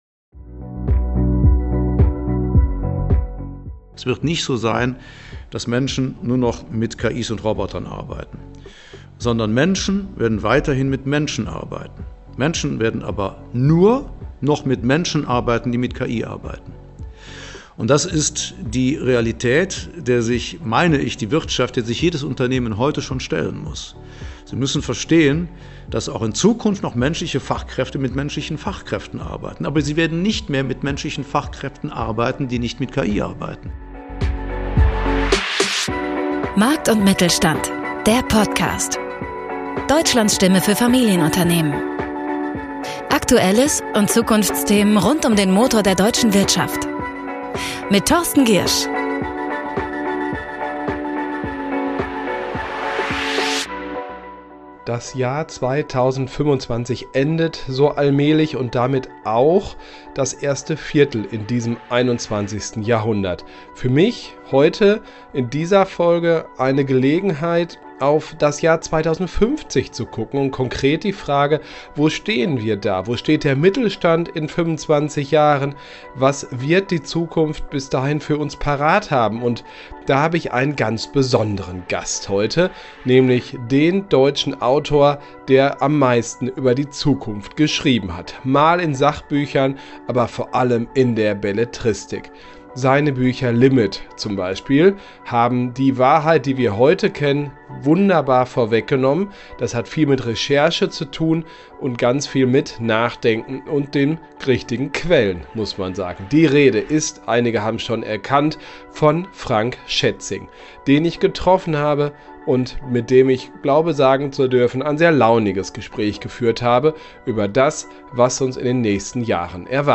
Frank Schätzing spricht über KI-Angst, Macht und Moral, Storytelling, Tech-Umbrüche und warum Unternehmen ohne KI nicht zukunftsfähig bleiben. Ein kluges, überraschendes Gespräch über 2050 – und über uns.